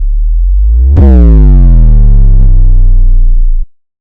BOOOM Sound
BOOOM Sound BOOOM Sound sound button BOOOM Sound sound effect BOOOM Sound soundboard Get Ringtones Download Mp3 Notification Sound